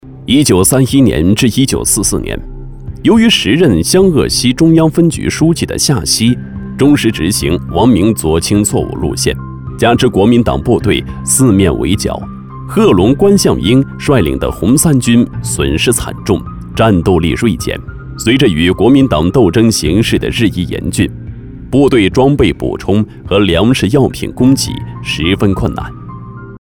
历史讲述男131号
自然诉说 文化历史
磁性响亮男音，激情有力。擅长历史抗战讲述、纪实讲述，专题题材。作品：抗战讲述。